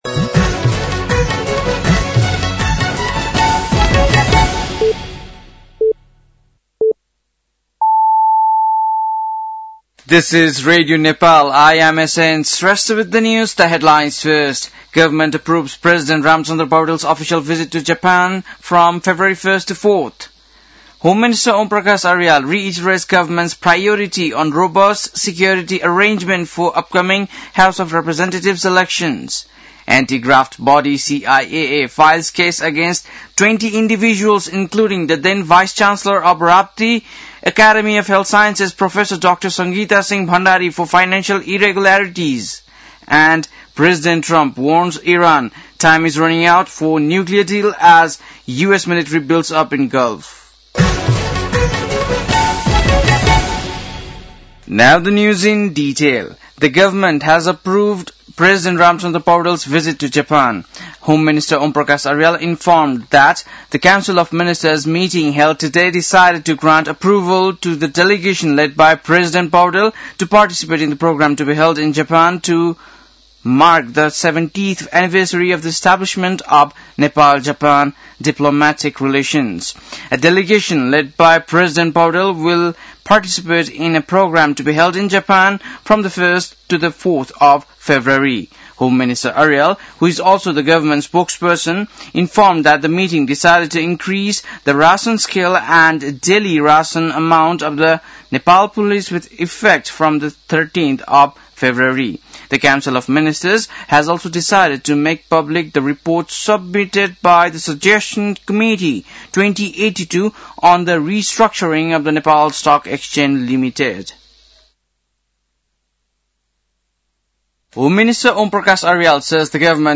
बेलुकी ८ बजेको अङ्ग्रेजी समाचार : १५ माघ , २०८२
8-pm-english-news-1-1.mp3